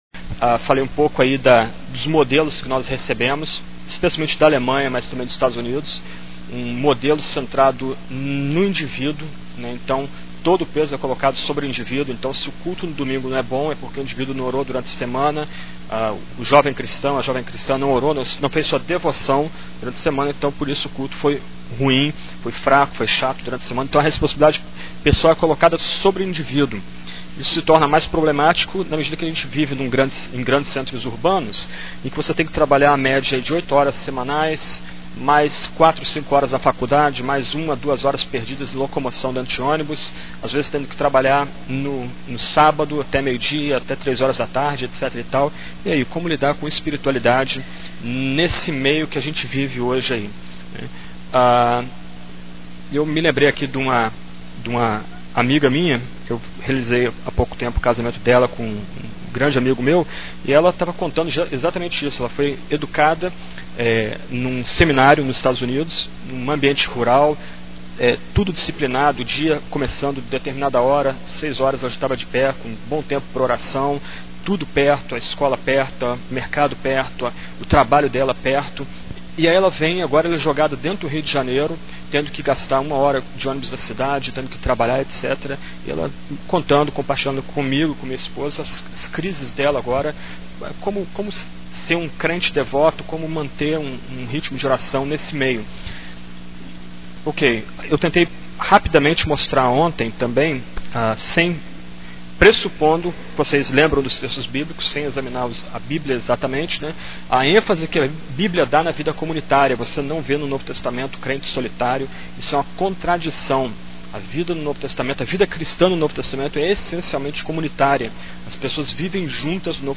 Conferência: 4ª Conferência Fiel para Jovens Tema: Firmados no Grande Deus - 1 João 2.3 Ano: 2006 Me